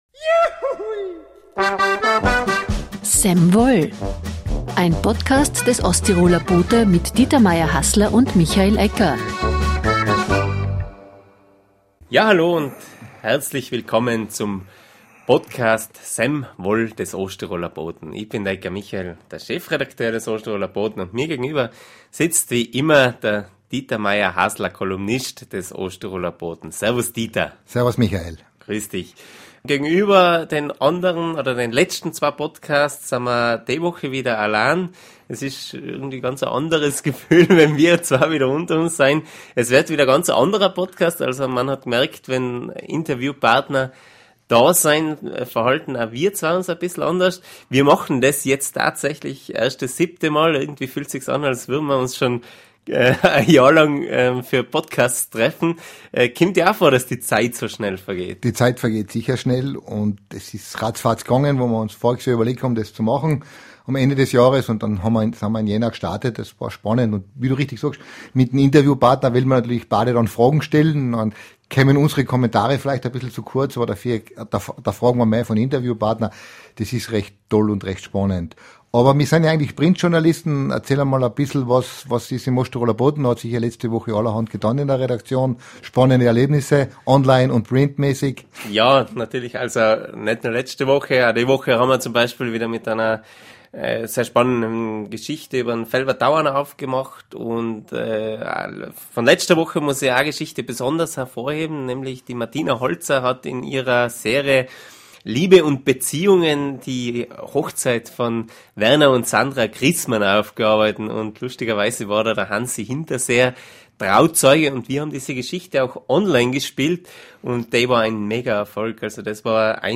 Am 3. Juni soll der Spar-Supermarkt am Brixnerplatz in Lienz endgültig geschlossen werden und an die Pustertaler Straße gegenüber der Brauerei übersiedeln. Diskussion über die Nahversorgung im Bezirk Lienz.